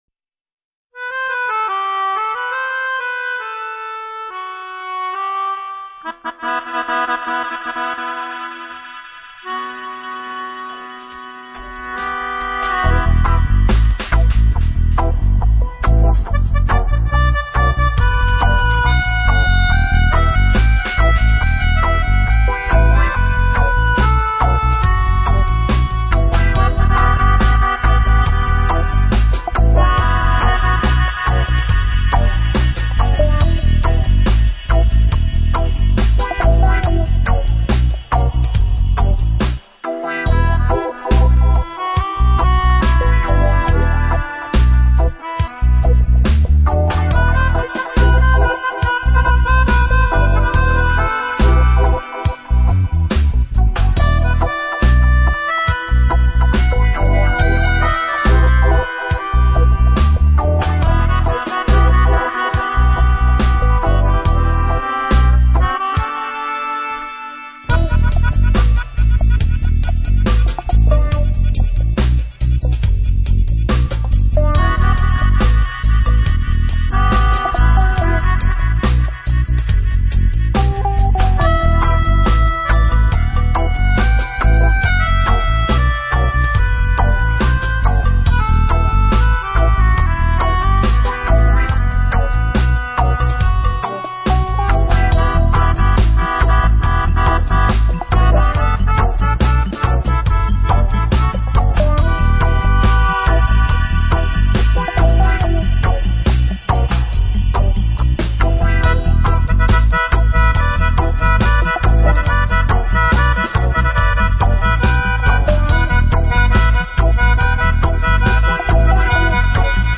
world
dub, reggae, hip hop and world music from the heart